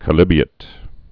(kə-lĭbē-ĭt, -lēbē-)